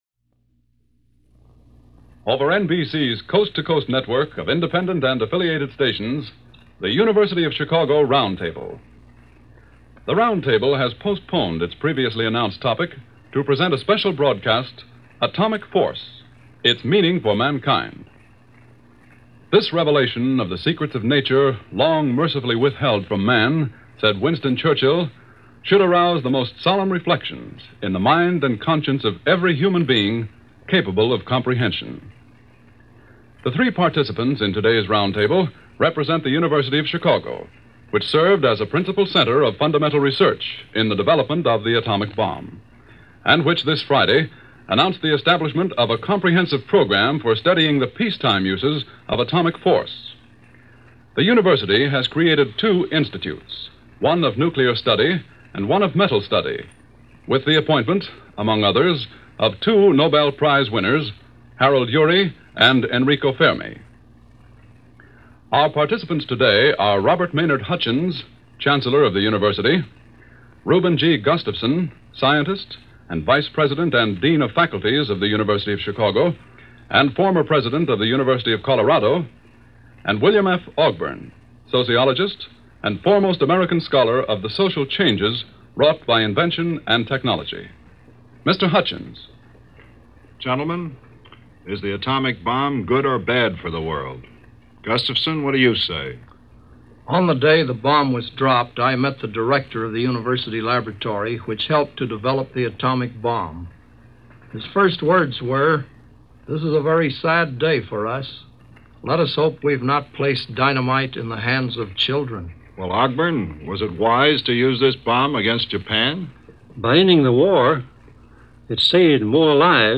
The Atomic Bomb - Now What? - August 12, 1945 - panel discussion on the potential uses, and misuses of the Atomic Bomb.
The long-term effect of the bombings were still unknown at the time of this broadcast, but a panel of scientists was quickly assembled for this special broadcast of The Chicago University Roundtable to discuss that question.